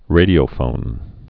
(rādē-ō-fōn)